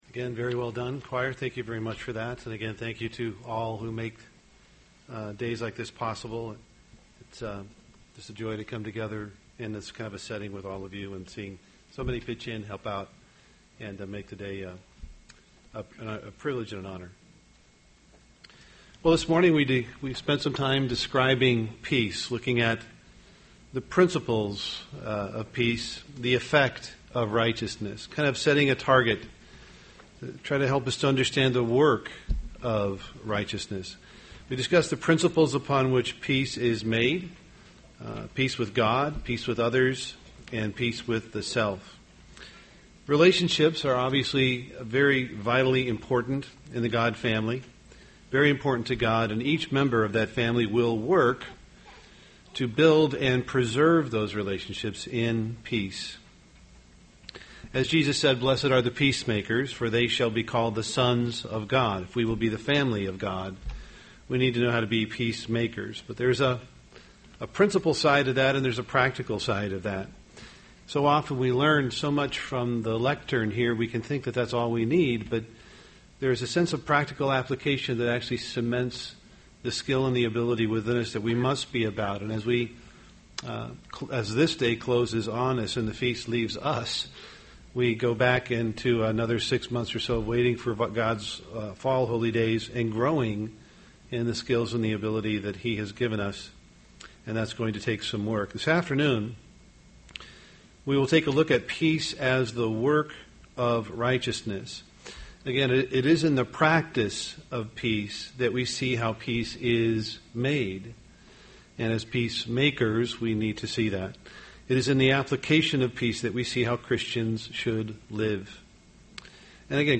UCG Sermon Unleavened Bread righteousness Studying the bible?